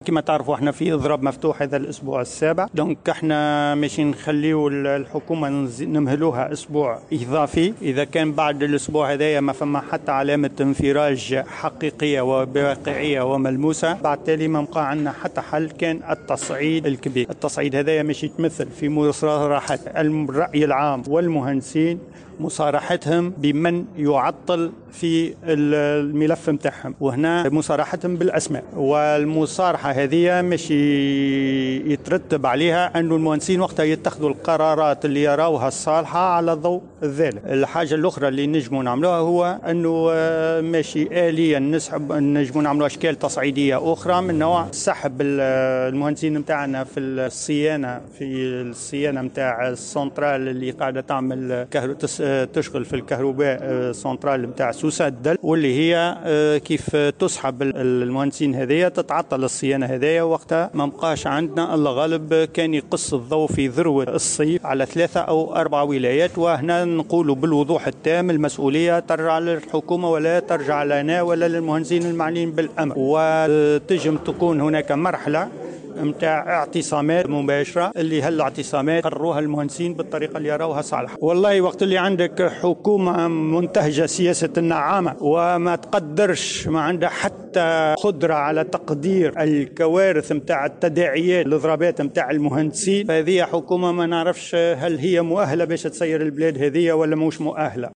وأضاف في تصريح اليوم لمراسلة "الجوهرة أف ام" على هامش ندوة صحفية عقدتها عمادة المهندسين، أن إضرابهم مشروع وأنهم لن يقبلوا المساس من حقوق منظوريهم بشكل تعسفي.